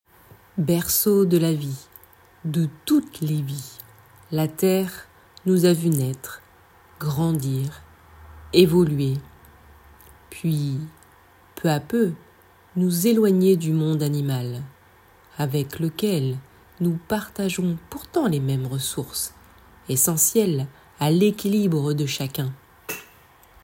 voix off